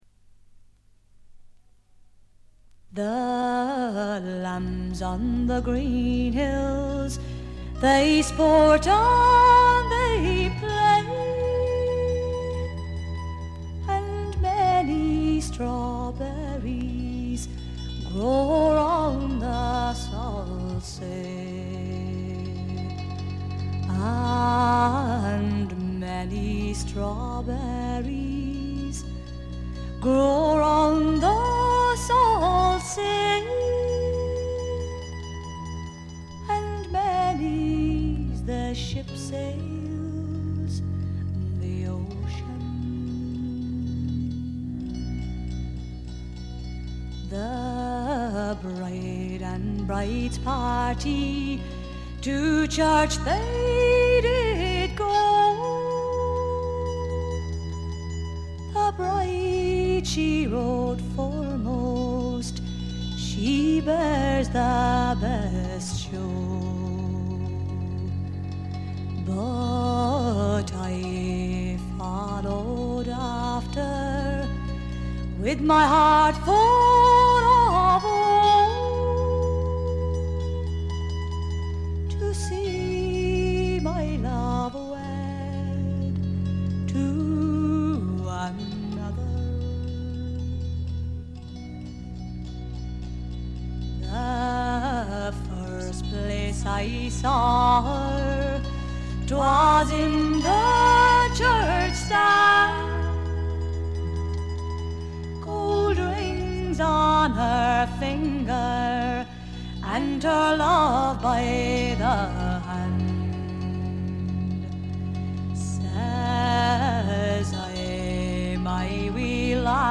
部分試聴ですが、わずかなノイズ感のみで良好に鑑賞できると思います。
アイリッシュトラッドの基本盤。
試聴曲は現品からの取り込み音源です。
Vocals, Banjo, Mandolin
Guitar, Fiddle, Mandolin, Vocals
Bass